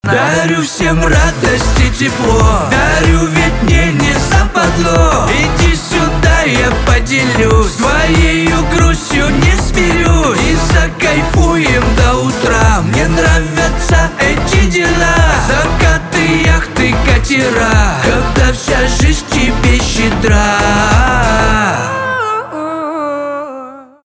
русский рэп
битовые , басы , аккордеон
позитивные